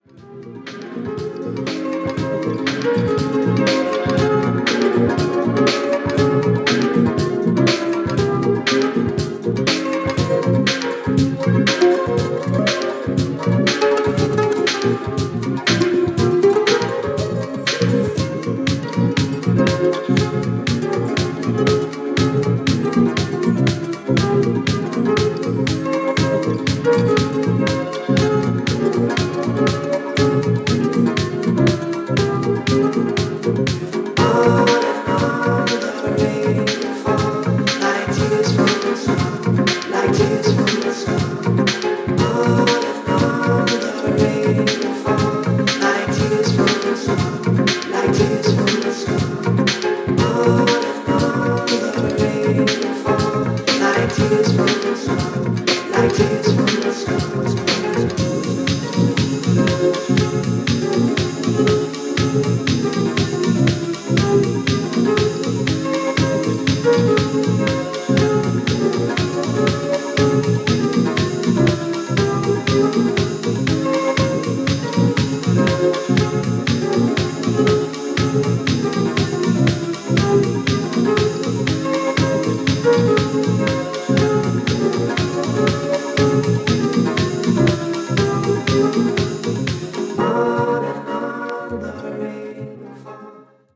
SAMPLE音源
Condition Media : EX-(音源録りしました)